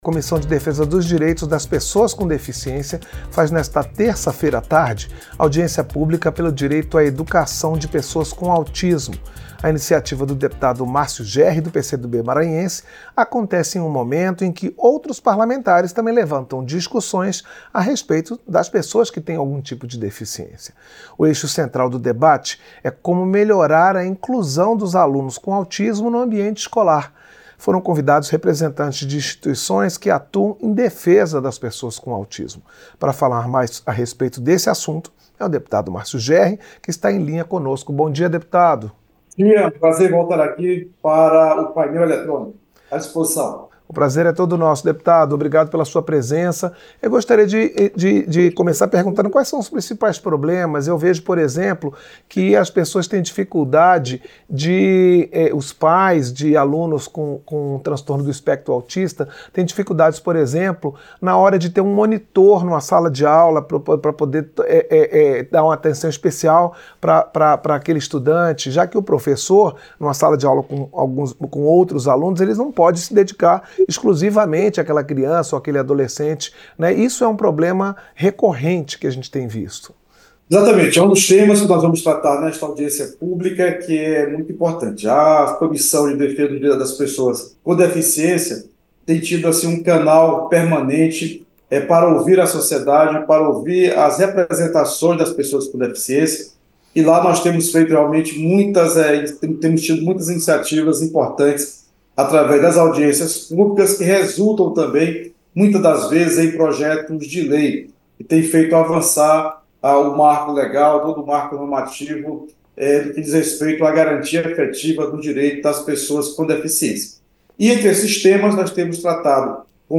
Entrevista - Dep. Márcio Jerry (PCdoB-MA)